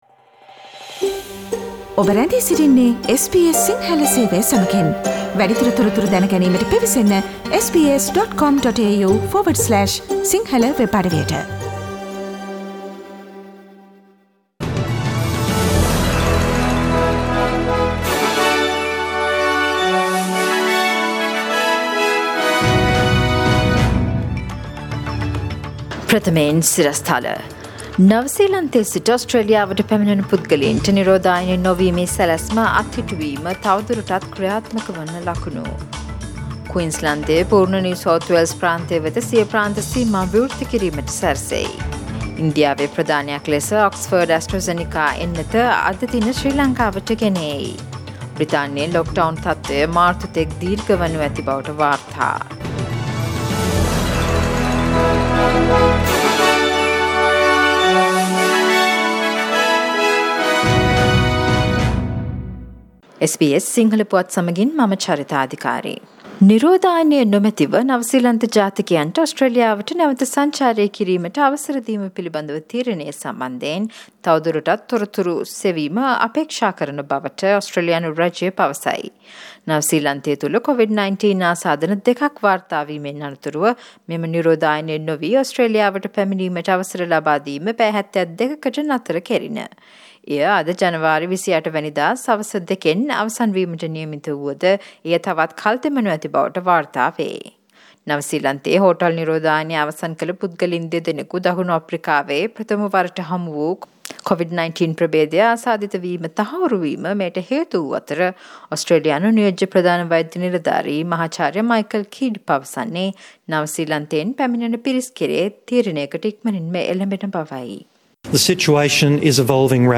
Today’s news bulletin of SBS Sinhala radio – Thursday 28 January